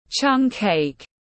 Bánh chưng tiếng anh gọi là Chung cake, phiên âm tiếng anh đọc là /chung keɪk/
Chung cake /chung keɪk/